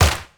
pcp_clap06.wav